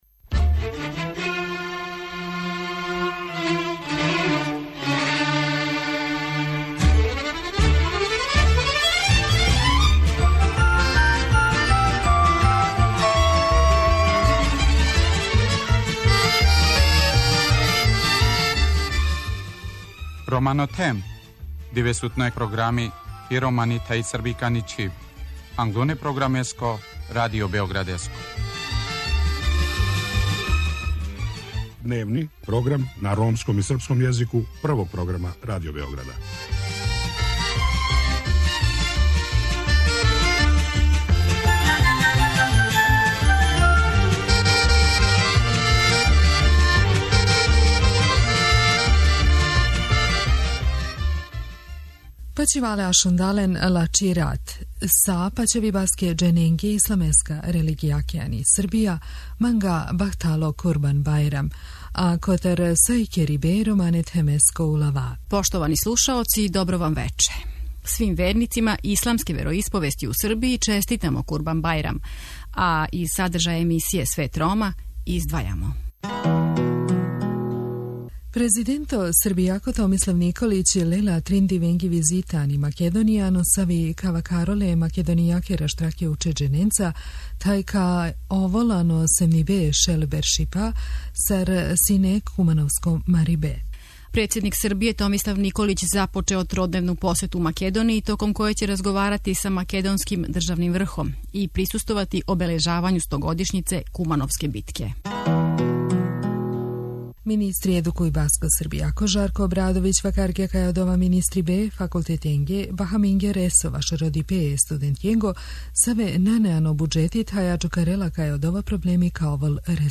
Гост емисије је Срђан Шаин, народни посланик и председник Ромске партије који говори о новчаним фондовима који ће бити искоришћени за унапређење положаја ромске националне заједнице у Србији.